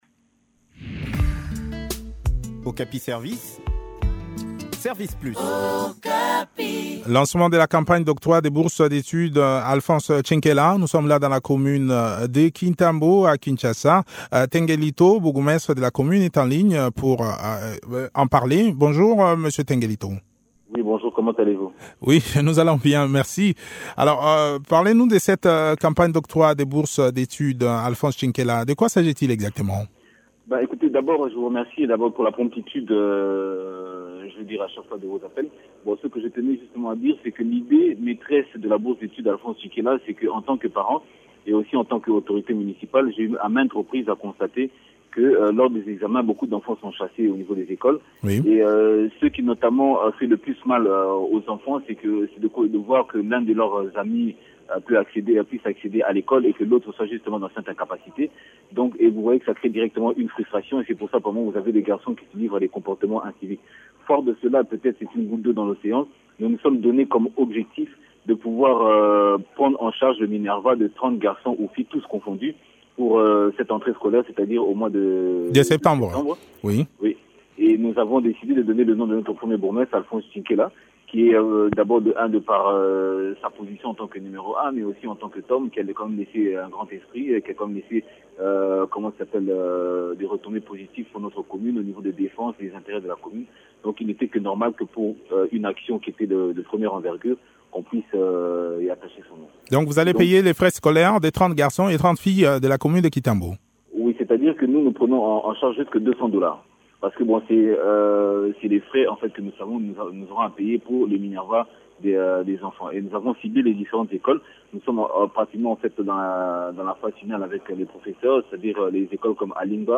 s’entretient avec Tenge Litho, bourgmestre de la maison communale de Kintambo.